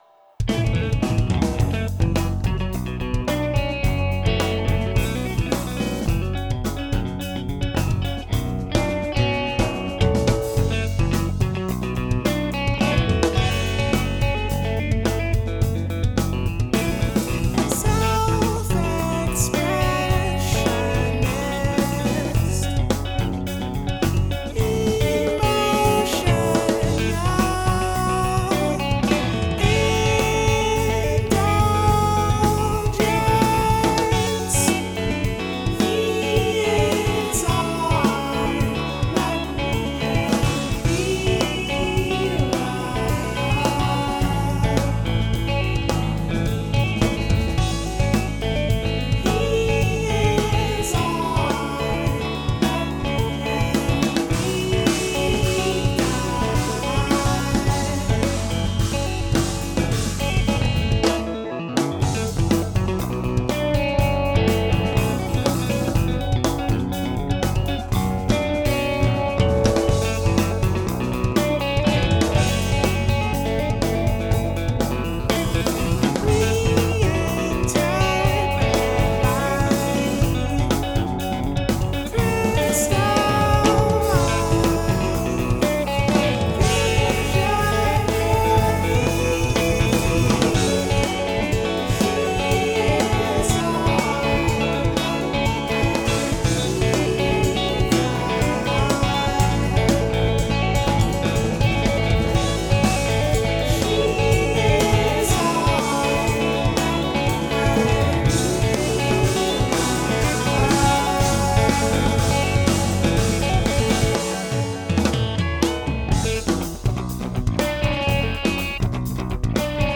drums
math rock